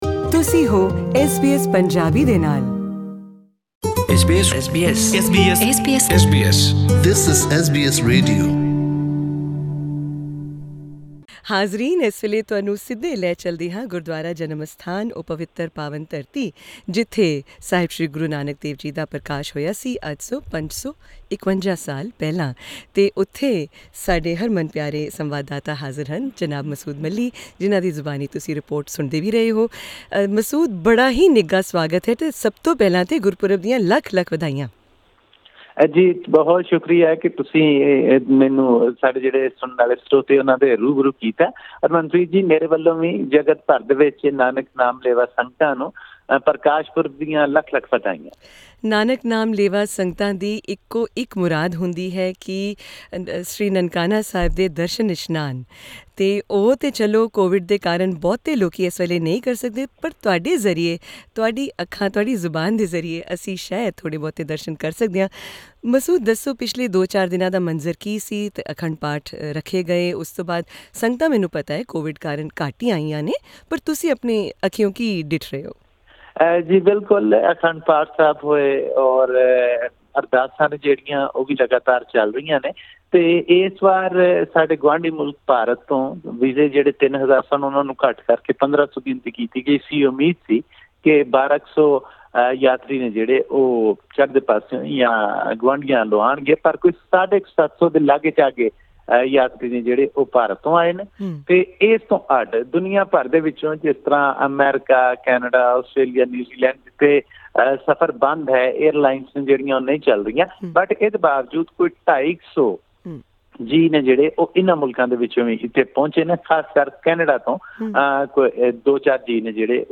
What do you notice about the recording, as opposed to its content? Members of the Sikh community from all over the world converged on Nankana Sahib Gurudwara in Pakistan to celebrate the 551st birth anniversary of Guru Nanak - the founder of the Sikh faith. Here is a report from Nanakana Sahib and six other gurudwaras in the precinct.